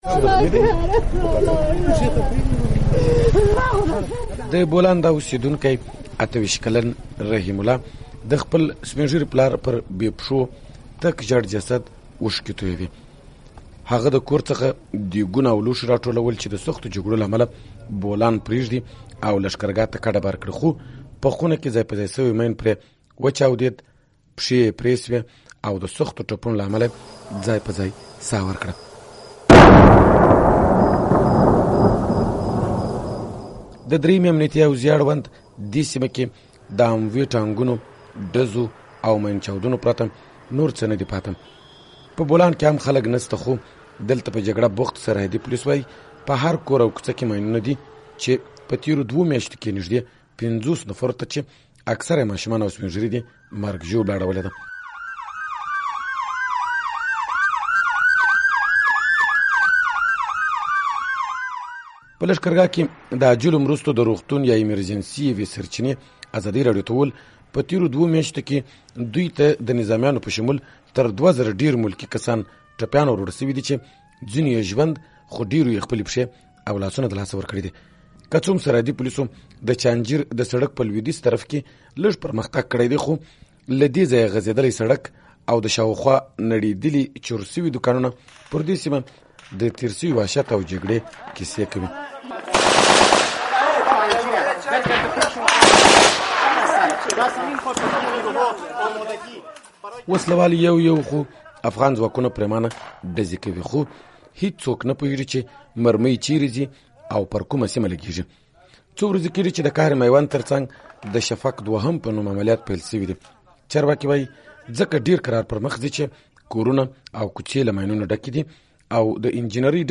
په دې اړه راپور د هغه ځوان په ژړا پیلېږي چې پلار يې تازه د ماین په یوه چاودنه کې وژل شوی دی.